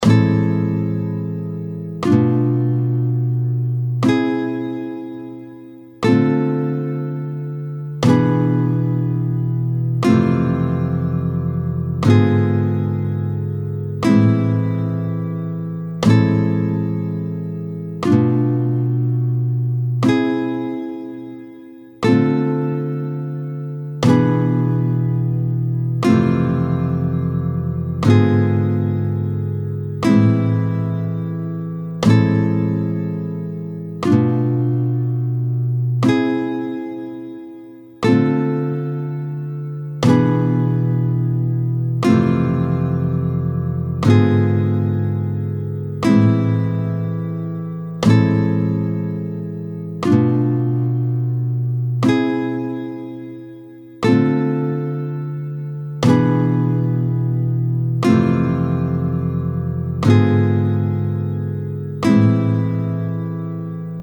24-06 Harmonisation à 4 sons de la gamme mineure harmonique, tempo 30